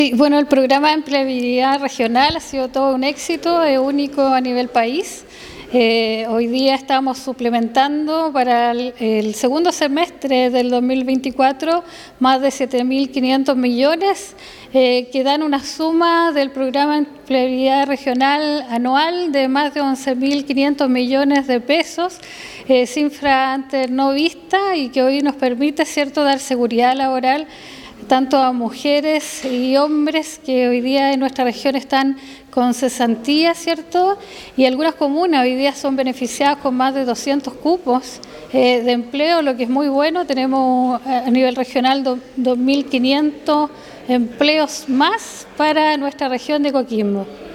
Consultado a la Gobernadora Krist Naranjo respecto al programa regional manifestó que es un gran logro y entrega tranquilidad a las familias, precisando,
CUNA-GOBERNADORA-KRIST-NARANJO.mp3